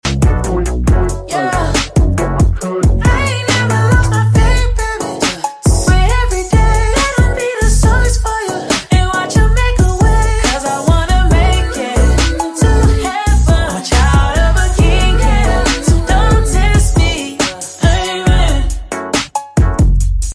a christian song